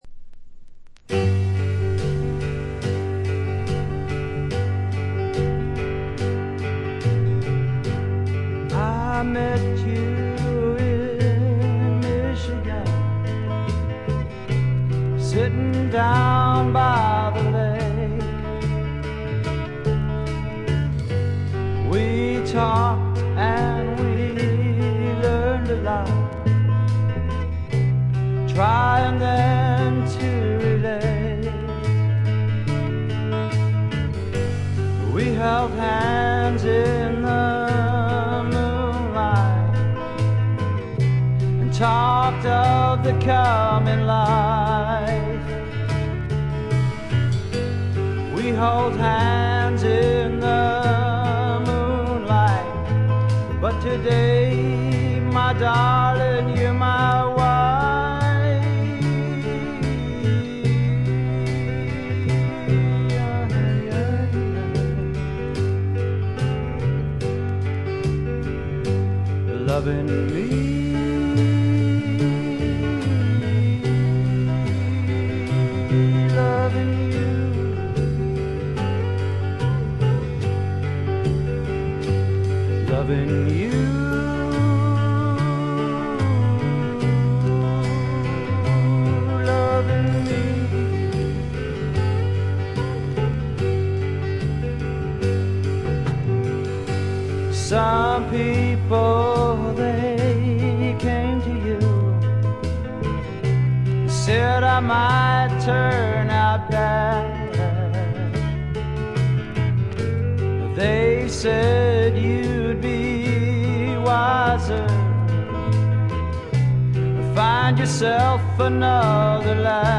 静音部での微細なチリプチ程度。
試聴曲は現品からの取り込み音源です。